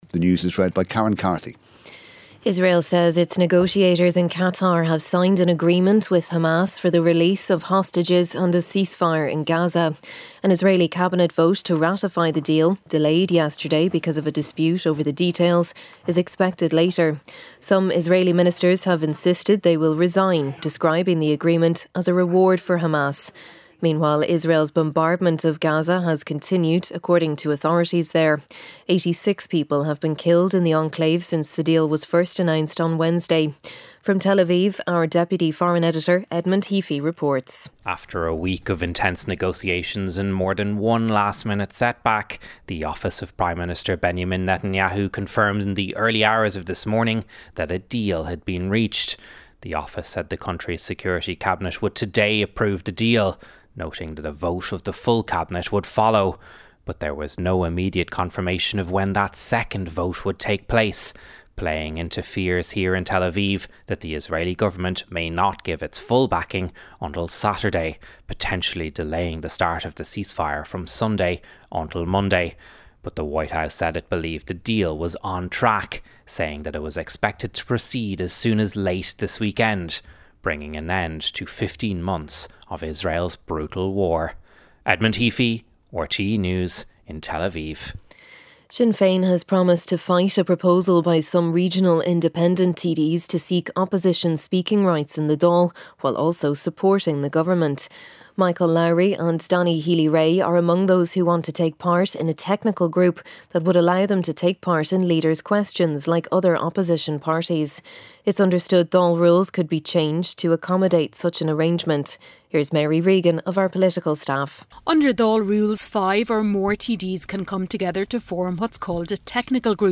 RTÉ's flagship news and current affairs radio programme and the most listened-to show in Ireland, featuring the latest news and analysis with Gavin Jennings, Audrey Carville, Áine Lawlor and Mary Wilson.